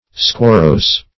squarrose - definition of squarrose - synonyms, pronunciation, spelling from Free Dictionary
Squarrose \Squar*rose"\ (skw[o^]r*r[o^]s" or skw[o^]r"r[=o]s`;